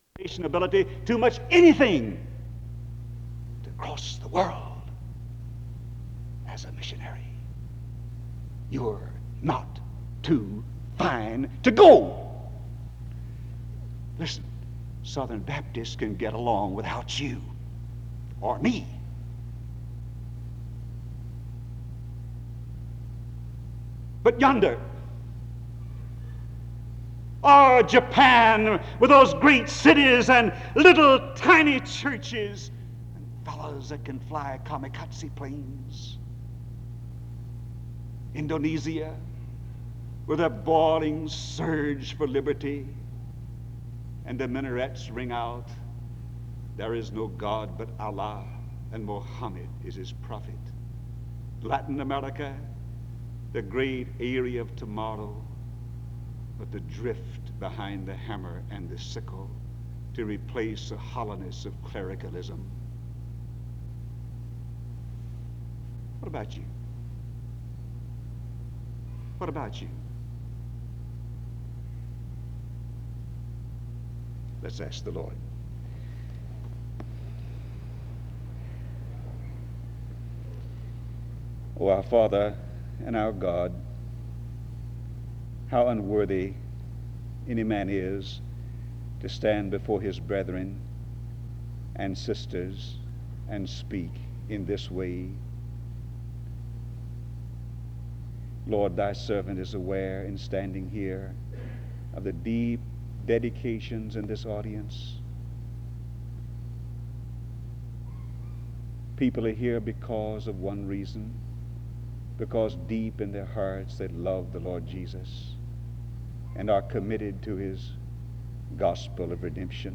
Download .mp3 Description The service begins with prayer (00:00-03:49).
The service continues with choral singing (08:07-09:56).